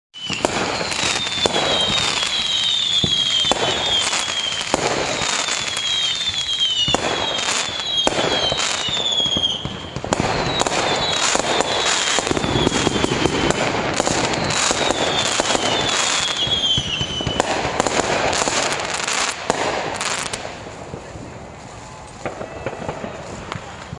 Download Firecracker sound effect for free.
Firecracker